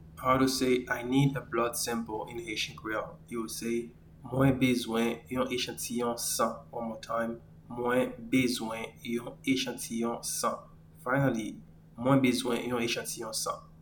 Pronunciation:
I-need-a-blood-sample-in-Haitian-Creole-Mwen-bezwen-yon-echantiyon-san.mp3